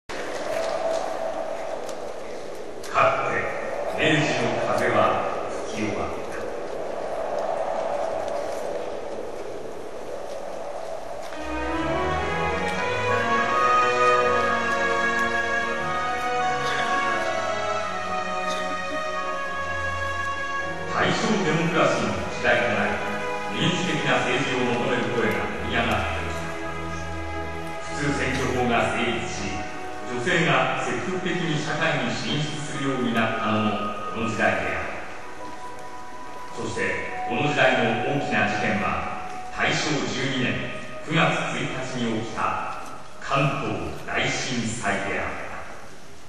ジ ョ イ ン ト リ サ イ タ ル
平成十九年八月二十六日 (日)　於：尼崎アルカイックホール